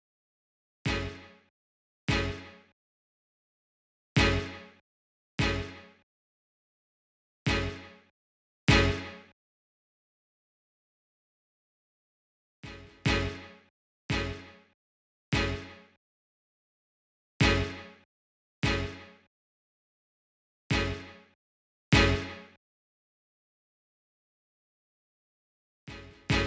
02 hits A.wav